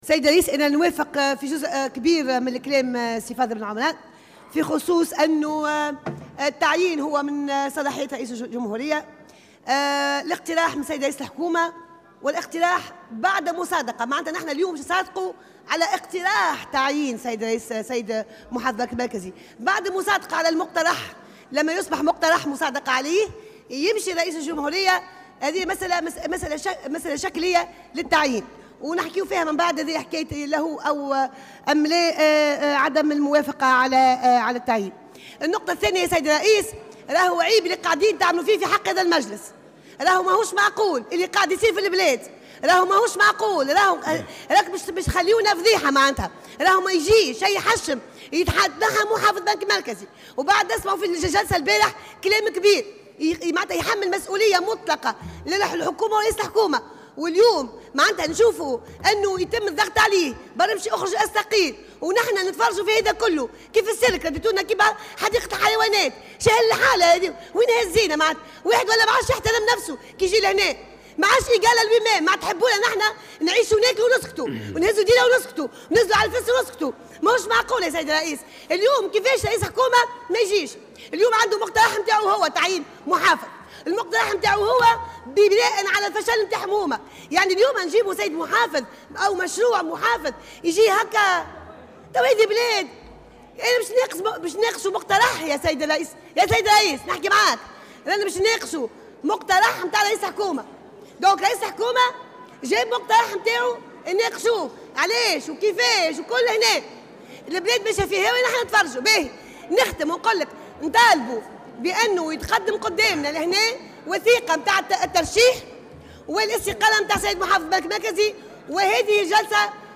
احتجت النائبة بمجلس نواب الشعب سامية عبو، عن عدم حضور رئيس الحكومة يوسف الشاهد، للجلسة العامة التي انطلقت بعد ظهر اليوم بالمجلس، والمخصصة للمصادقة على تعيين مروان العباسي محافظا للبنك المركزي التونسي خلفا للشاذلي العياري.